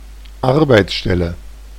Ääntäminen
IPA : /pəˈzɪʃən/